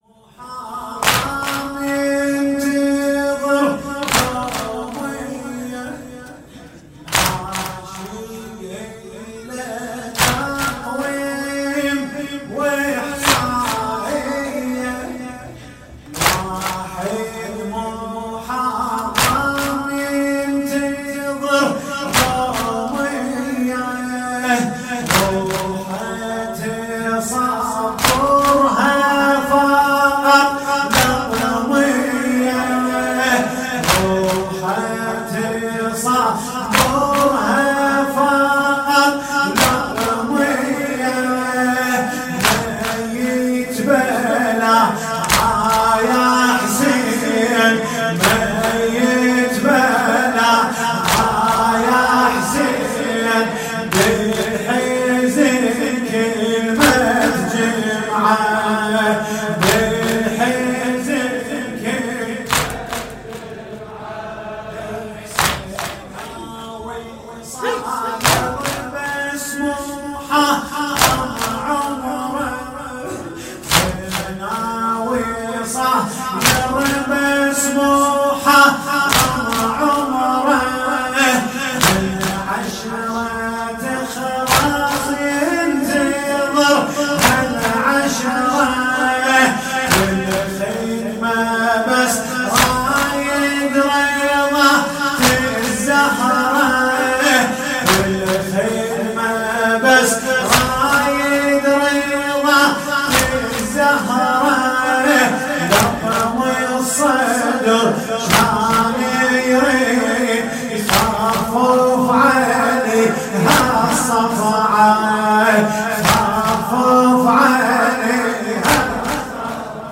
حسینیه انصارالحسین علیه السلام
شب اول محرم 96 | واحدشلاقی | واحد محرم ینتظر یومیه